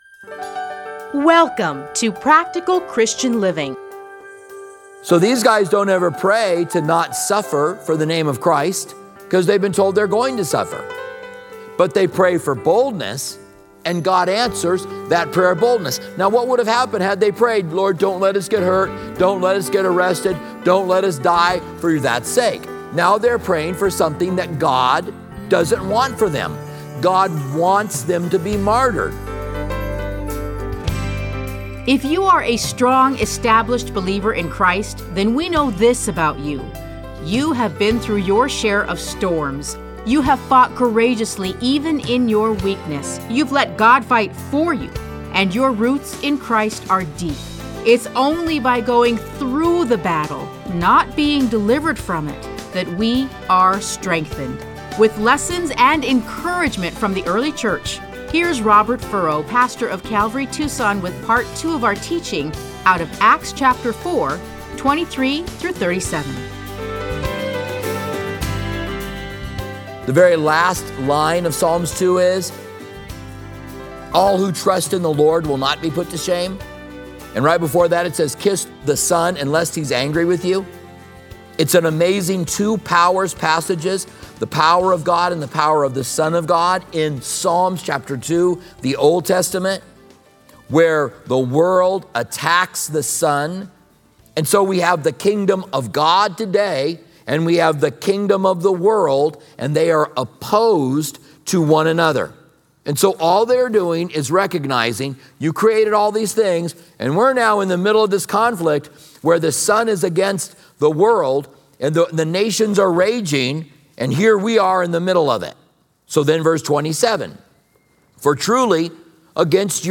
Listen to a teaching from Acts 4:23-37.